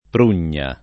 prugna [ pr 2 n’n’a ]